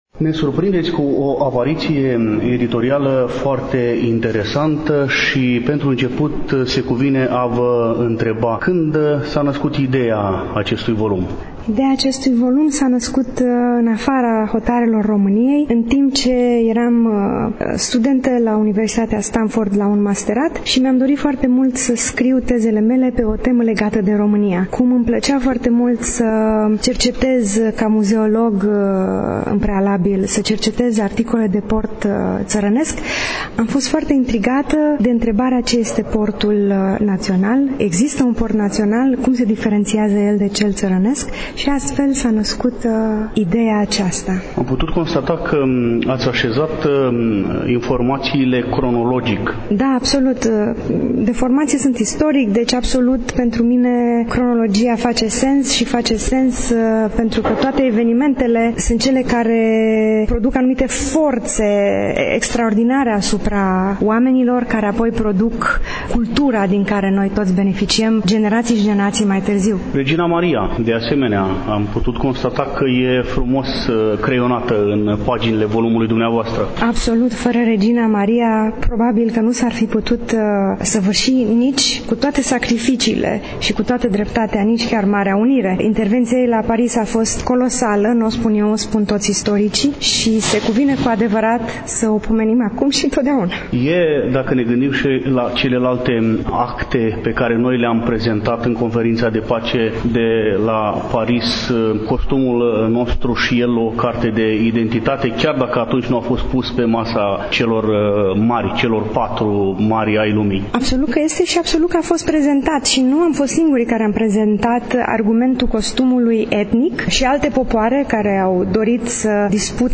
„Viitorul ţării îl țese femeia”, manifestare care s-a desfășurat, la Iași, în incinta Sălii „Petru Caraman”, Muzeul Etnografic al Moldovei, Complexul Muzeal Național „Moldova” Iași.